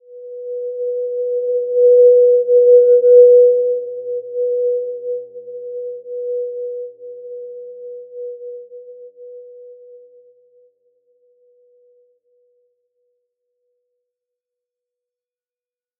Simple-Glow-B4-mf.wav